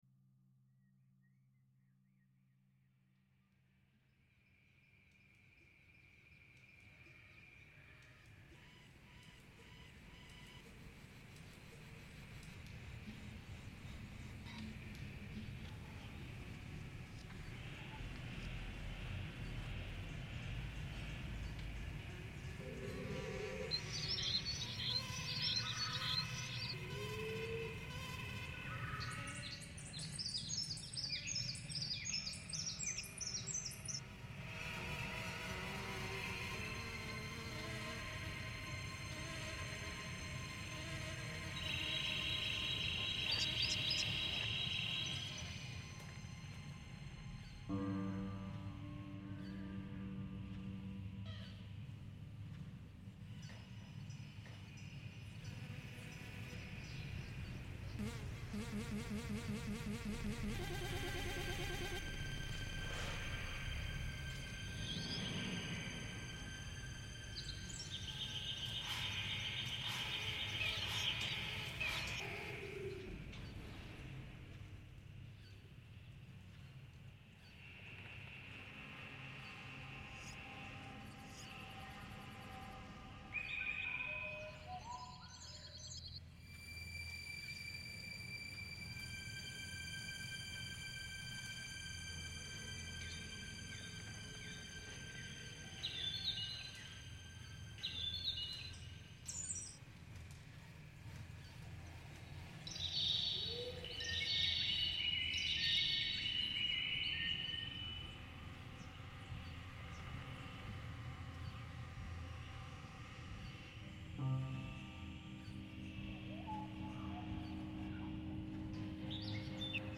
Marseilles lockdown sound reimagined